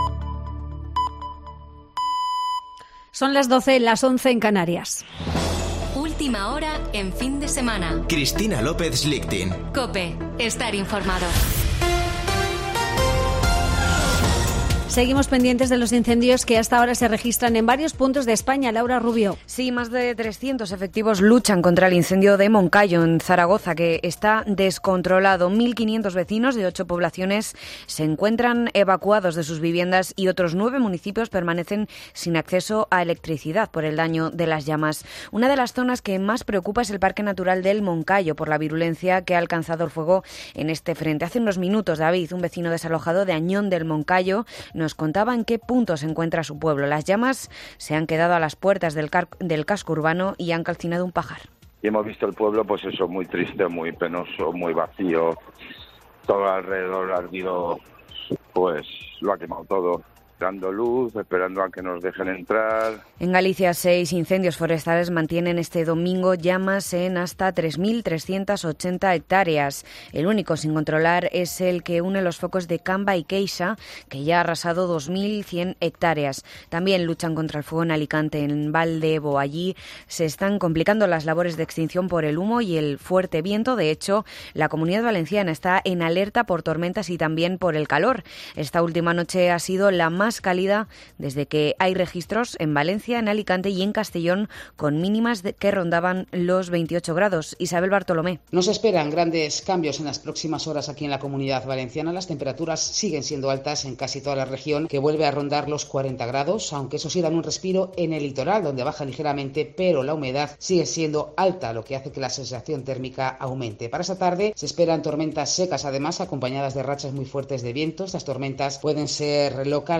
AUDIO: Boletín de noticias de COPE del 14 de agosto de 2022 a las 12.00 horas